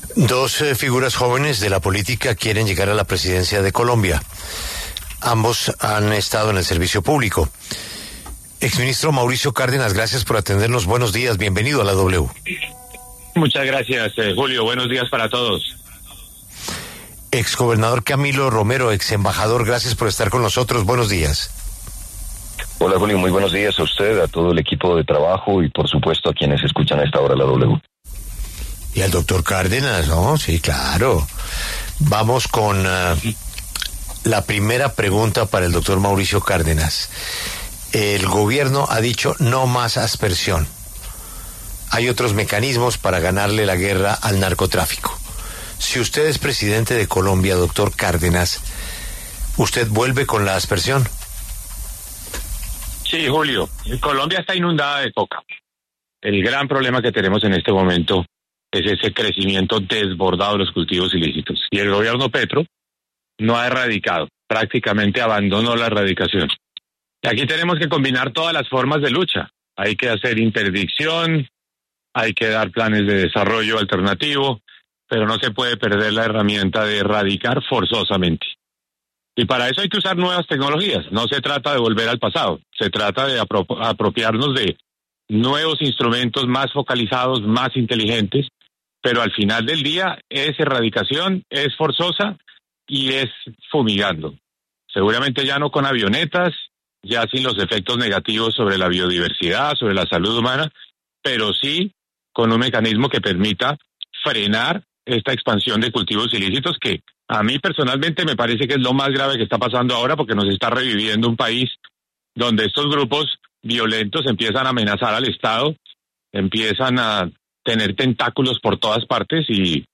Debate Mauricio Cárdenas y Camilo Romero: ¿Cómo combatir el narcotráfico y acabar cultivos ilícitos?
Dos precandidatos presidenciales con visiones diferentes como Mauricio Cárdenas y Camilo Romero debatieron en La W sobre la erradicación forzada, el narcotráfico y la Asamblea Nacional Constituyente.